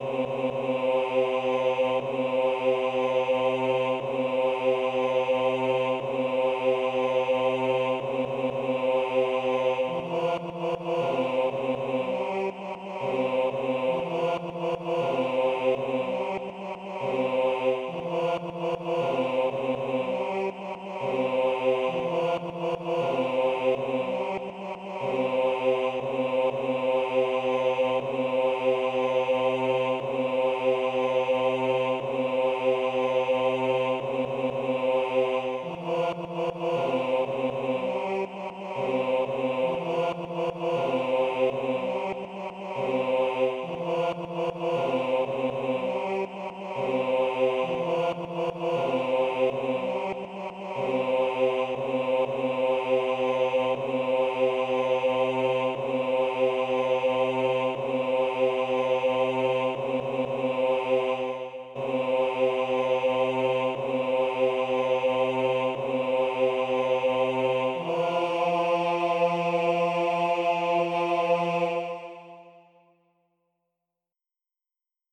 Basse - HAH voix Bp